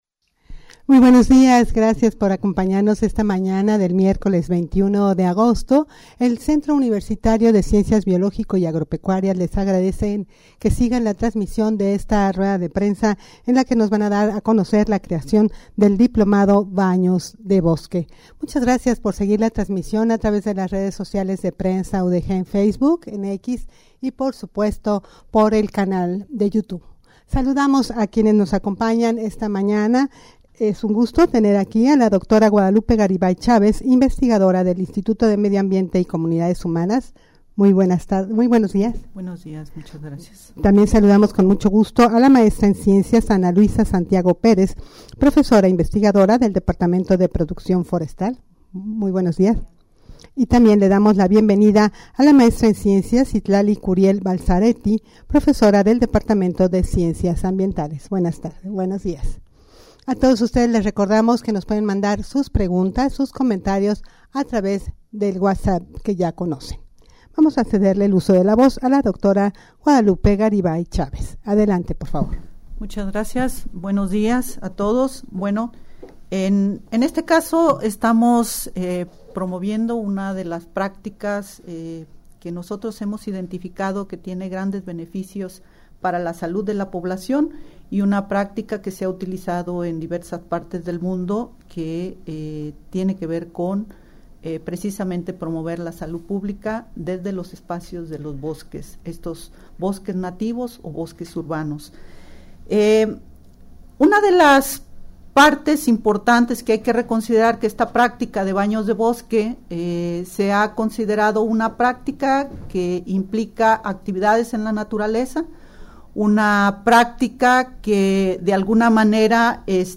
Audio de la Rueda de Prensa
rueda-de-prensa-para-dar-a-conocer-la-creacion-del-diplomado-banos-de-bosque.mp3